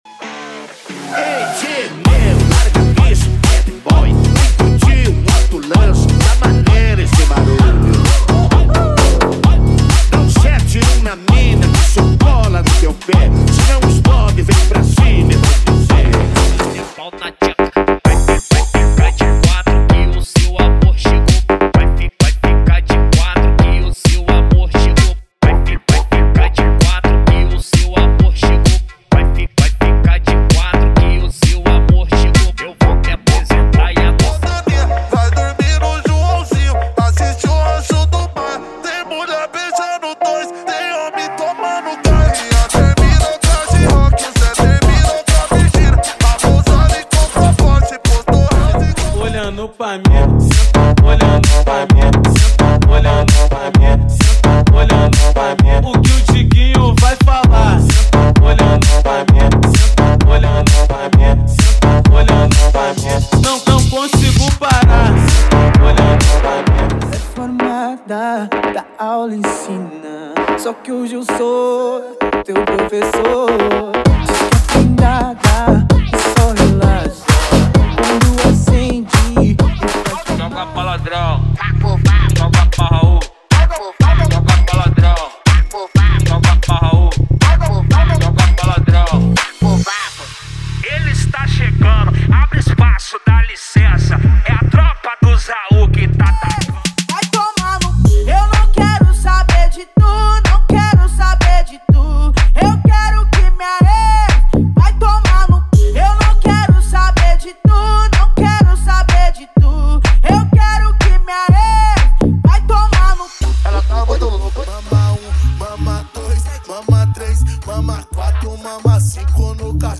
✔ Músicas sem vinhetas